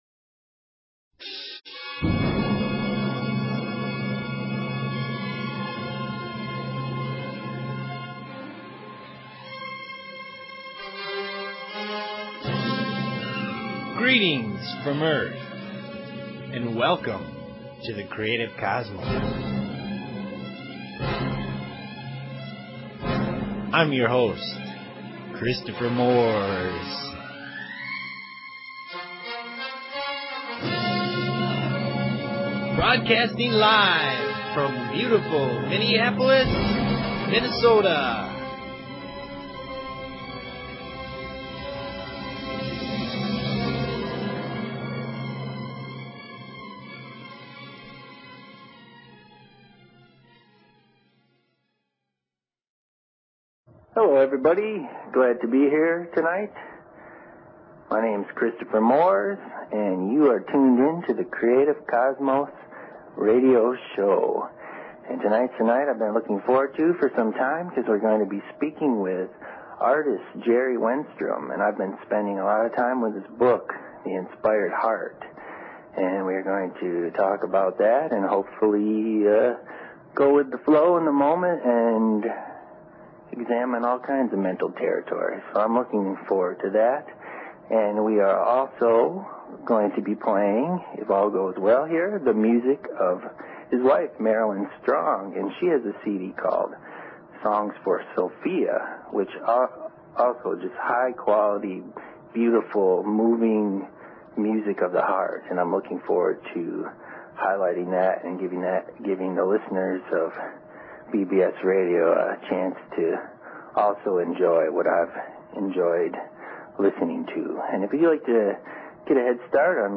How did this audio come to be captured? Zen, Chakras, Dreams, Astral Plane, Aliens, Spirit Communication, Past Lives, and Soul Awakening are all in play. There are no boundaries as the conversation goes where it needs to in the moment.